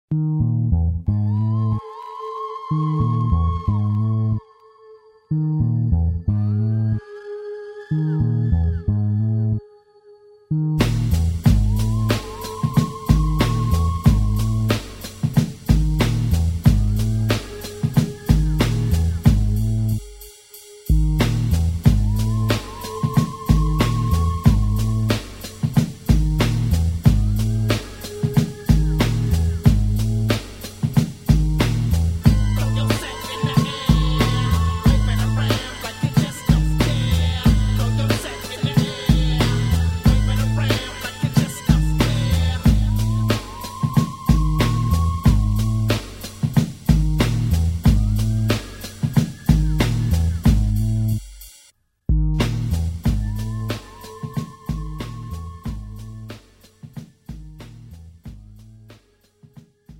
장르 pop 구분 Premium MR